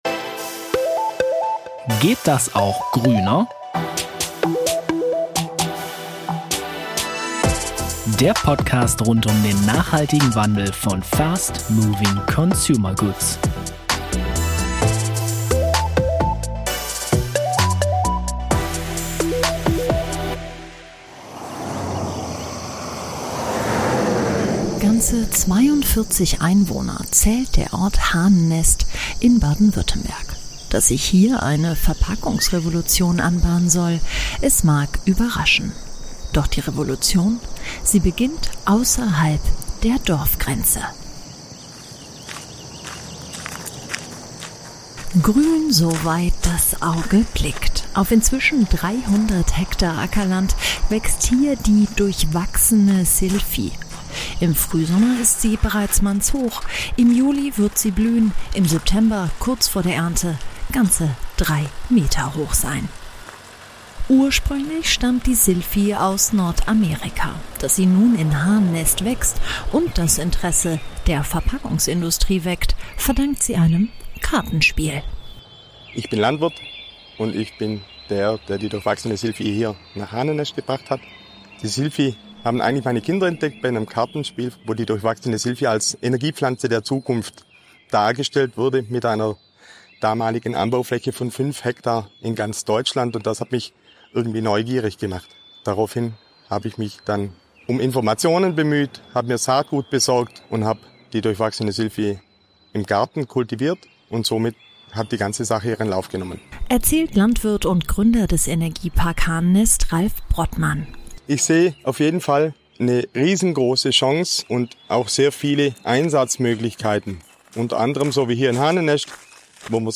Könnte die Silphie-Pflanze die Antwort auf unsere Verpackungsprobleme sein? Forscher und Hersteller erklären, was dahinter steckt.